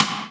HR16B RIM 02.wav